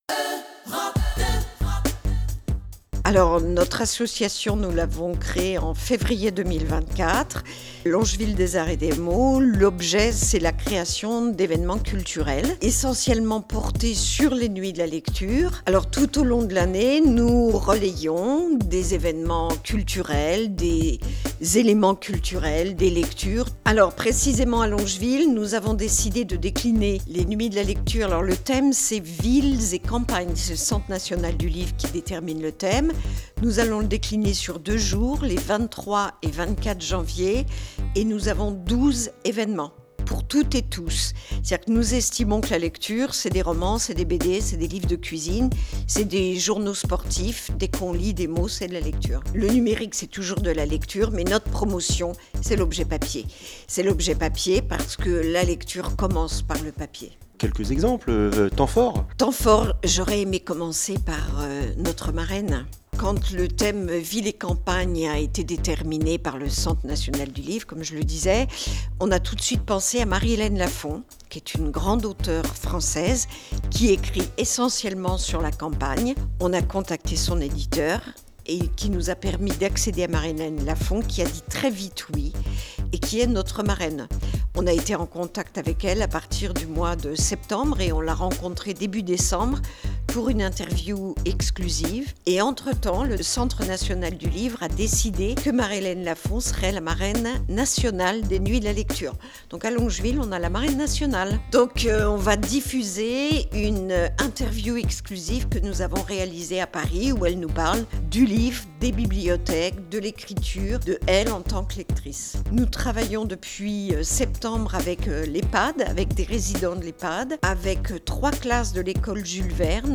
Les explications de